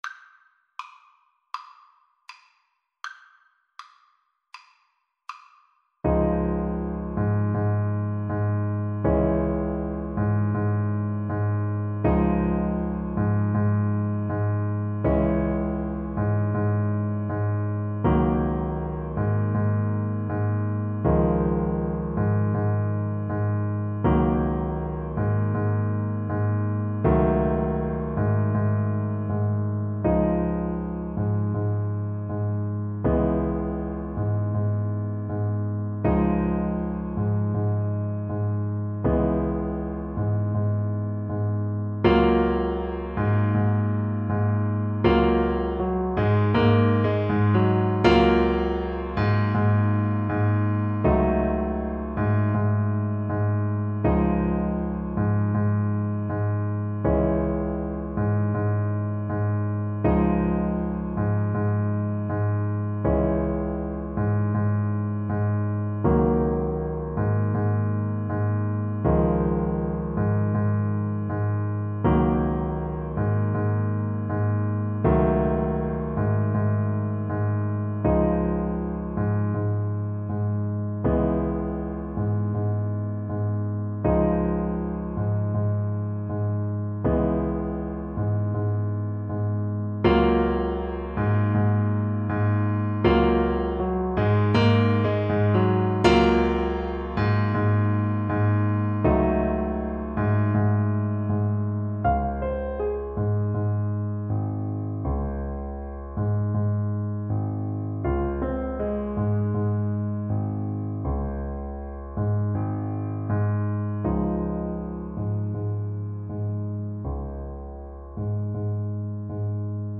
Alto Saxophone
A relaxed melody with a Rumba beat.
Andante
World (View more World Saxophone Music)